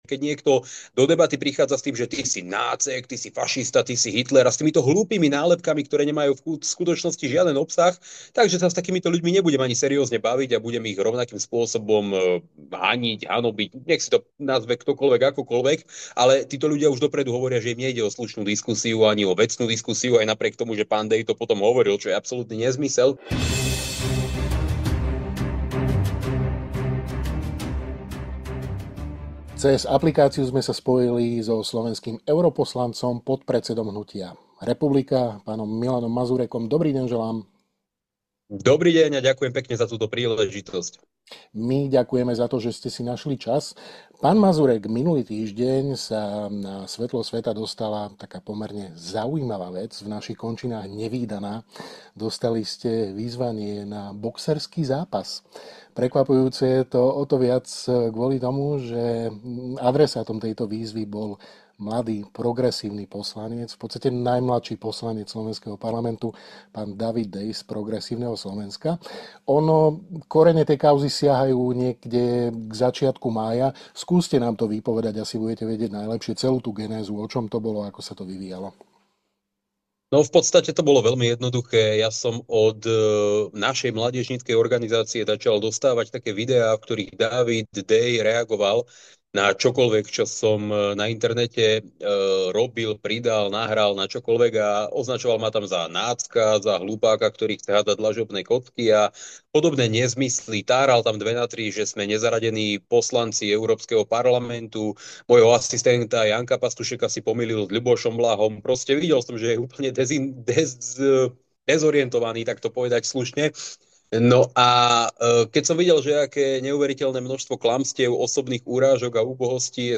Rozprávali sme sa s europoslancom a podpredsedom hnutia Republika, Bc. Milanom Mazurekom.